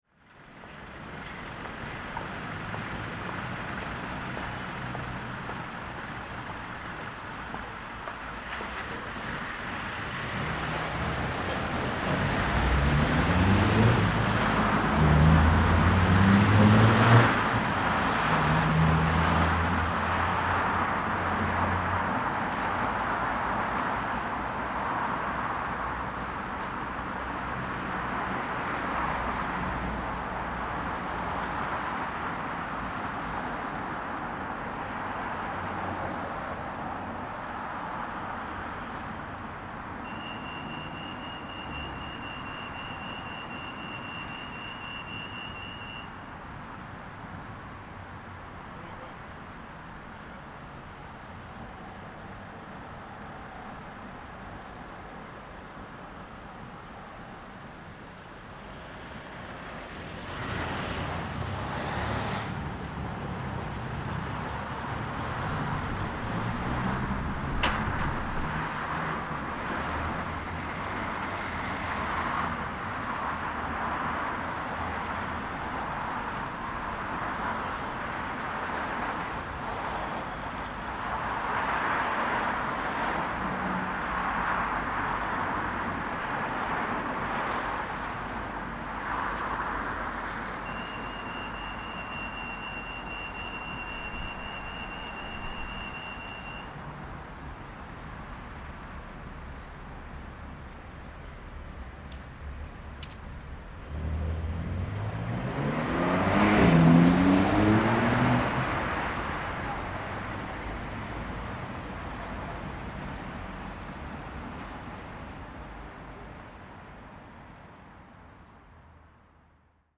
Recording in front of the local university, however, space has begun to be quieter and subtle sounds in the distance. Beginning of Lockdown 2 in Belfast.